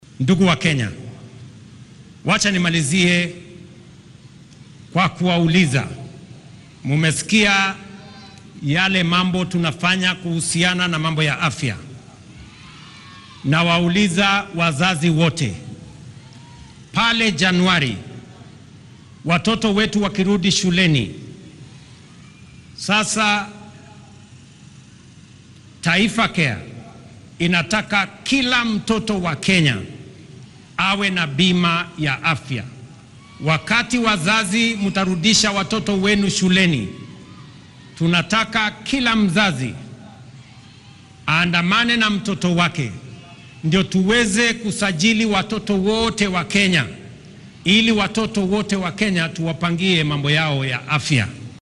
Madaxweynaha dalka William Ruto oo maanta khudbad ka jeediyay xuska qaran ee maalinta Jamhuri Day oo lagu qabtay fagaaraha Uhuru Gardens ee ismaamulka Nairobi ayaa sheegay in hadda laga gudbay in shacabka loo ballan qaado waxyaabo dheeraad ah.